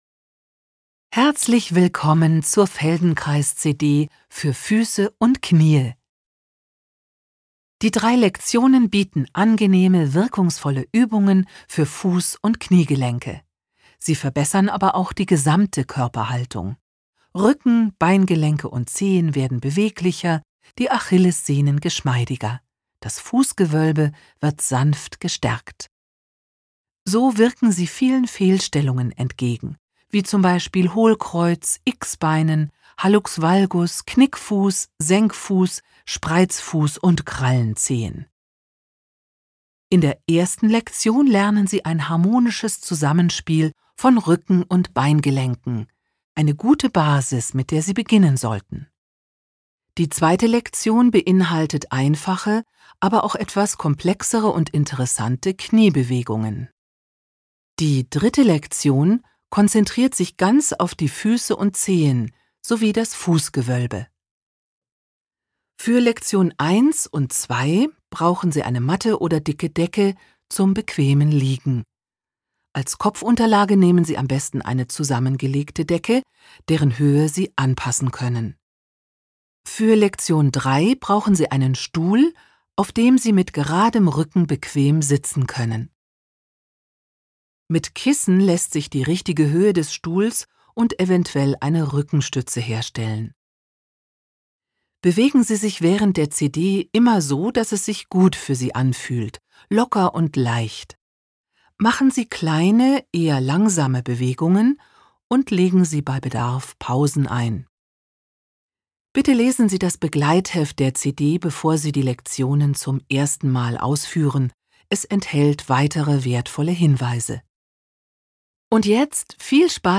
Feldenkrais - Bewegte, schmerzfreie Füße und Knie Hörbuch